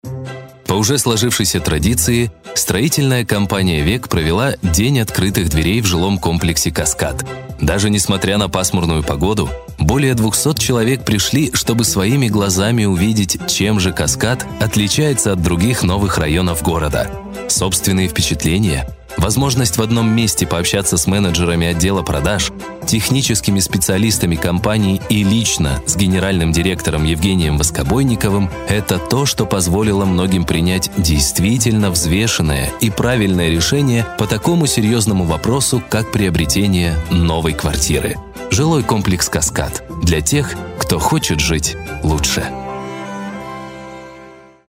Микрофон - Sontronics STC-2, предусилитель Long stereo chanel, Звуковая карта ESI U24xl.
Демо-запись №1 Скачать